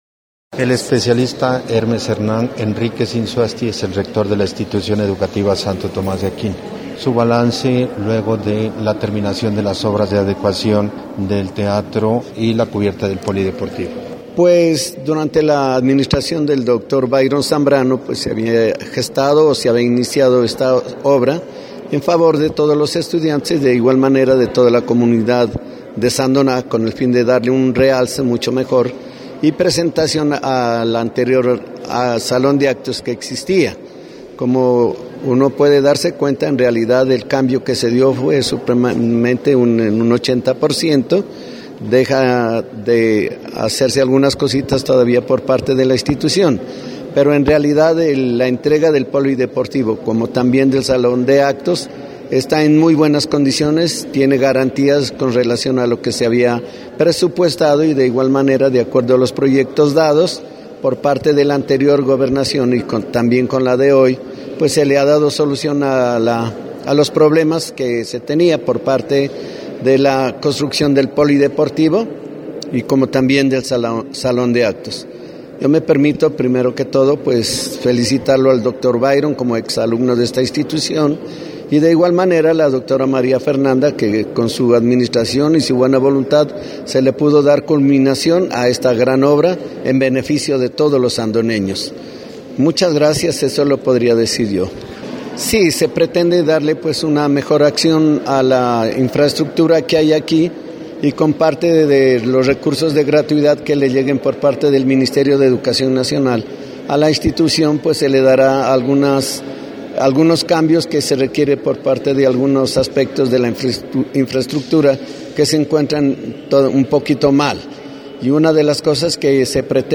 Los ingenieros responsables del proyecto de adecuación del salón de actos y construcción de la cubierta del polideportivo de la Institución educativa Tomás de Aquino de Sandoná hicieron entrega de las obras este viernes en horas de la mañana.